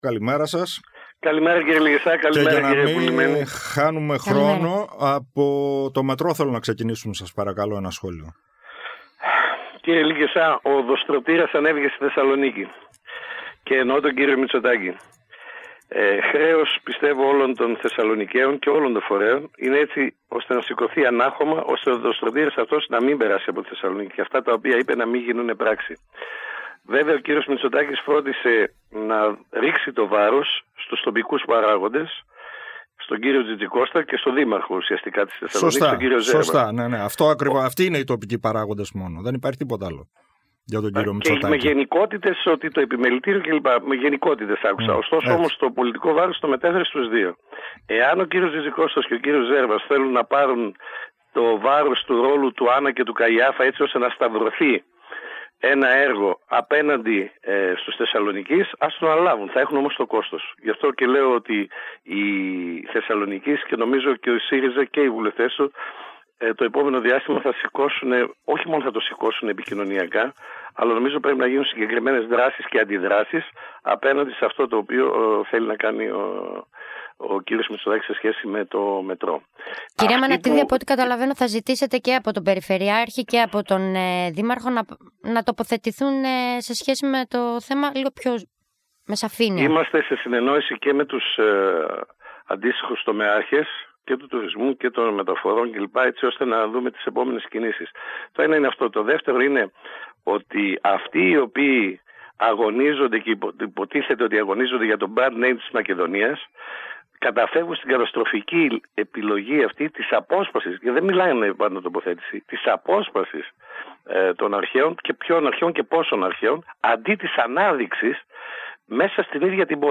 Για το Μετρό της Θεσσαλονίκης, τον ΟΑΣΘ, τους συνδικαλιστές και την εγκατάλειψη του οράματος που είχε η προηγούμενη κυβέρνηση για την πόλη ως πρωτεύουσα των Βαλκανίων μίλησε σήμερα στο Κόκκινο 91,4 ο βουλευτής Α΄Θεσσαλονίκης του ΣΥΡΙΖΑ, Γιάννης Αμανατίδης.